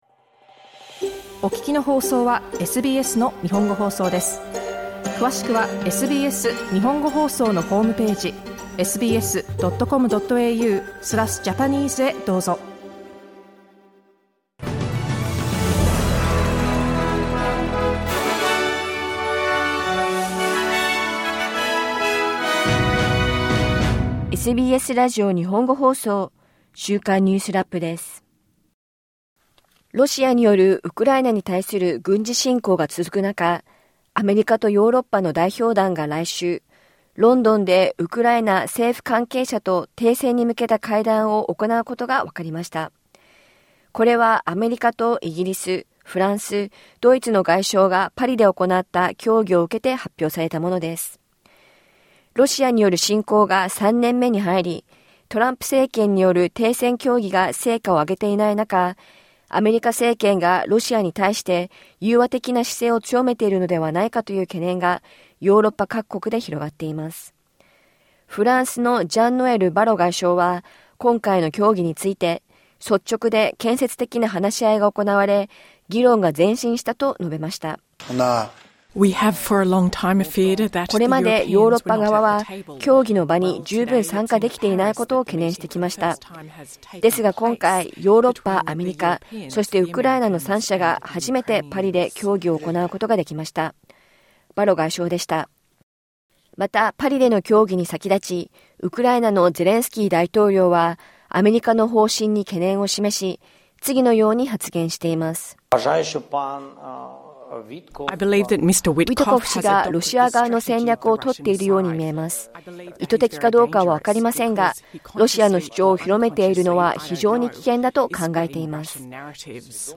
アメリカとヨーロッパの代表団が来週、ロンドンでウクライナ政府関係者と停戦に向けた会談を行うことがわかりました。オーストラリアの雇用市場は安定した状態が続いており、3月の失業率はわずかに上昇して4.1％となりました。シドニー東部のボンダイジャンクションで発生した、無差別殺傷事件から1年を迎えた今週、犯人に勇敢に立ち向かた男性らが当時を振り返りました。1週間を振り返るニュースラップです。